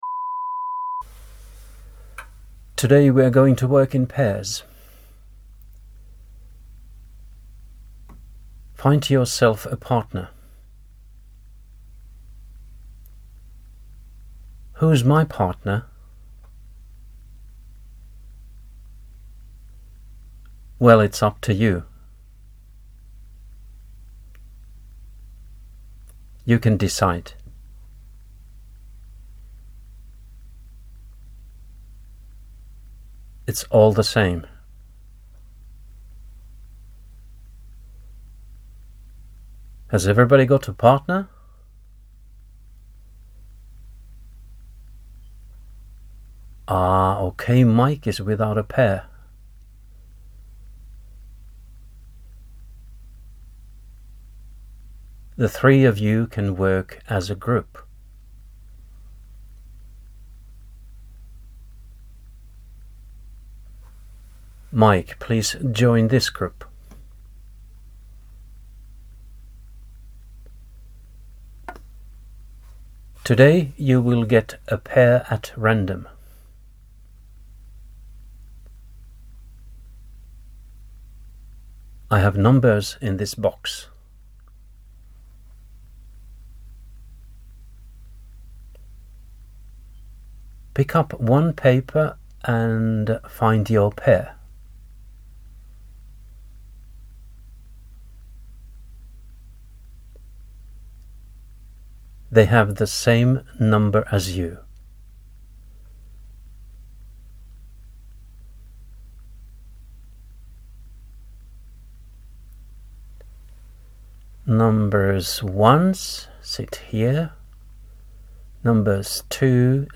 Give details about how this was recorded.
The recordings already have pauses in them so there is usually no need to stop in between.